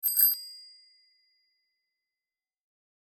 دانلود آهنگ دوچرخه 11 از افکت صوتی حمل و نقل
دانلود صدای دوچرخه 11 از ساعد نیوز با لینک مستقیم و کیفیت بالا
جلوه های صوتی